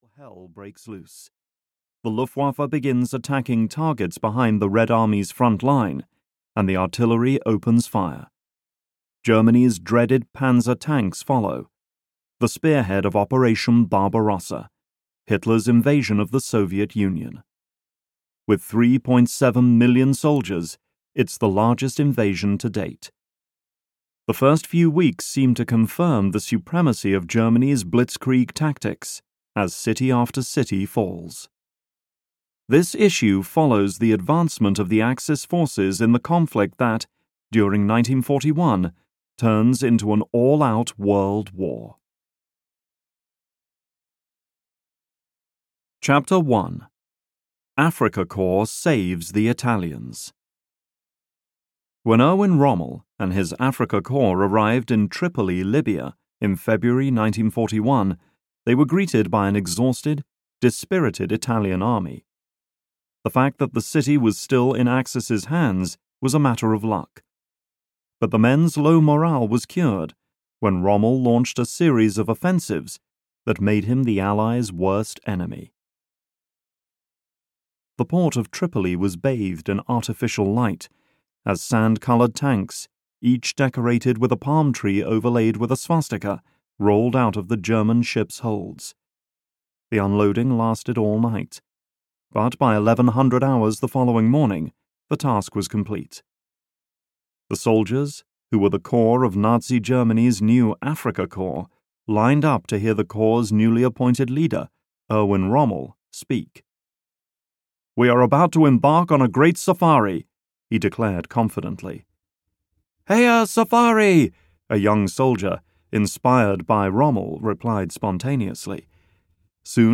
Total War (EN) audiokniha
Ukázka z knihy